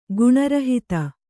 ♪ guṇa rahita